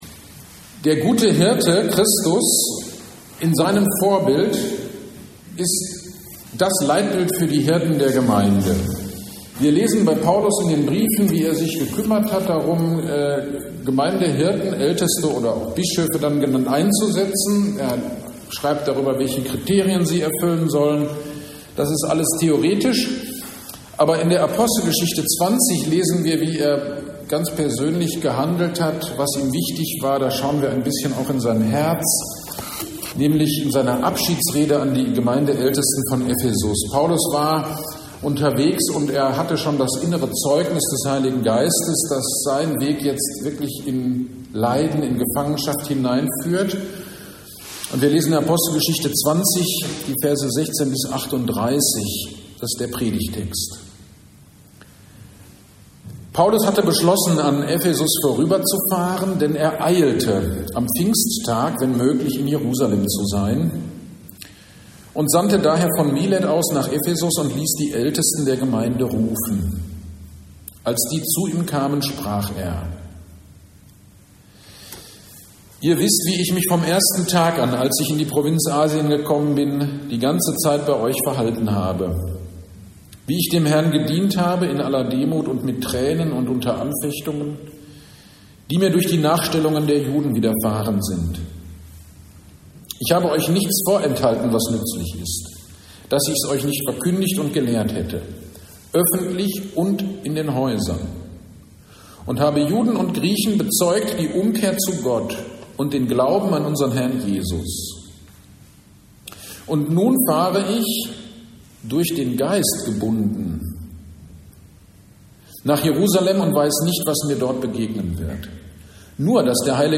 GD am 04.05.2025 Predigt zu Apostelgeschichte 20, 16-38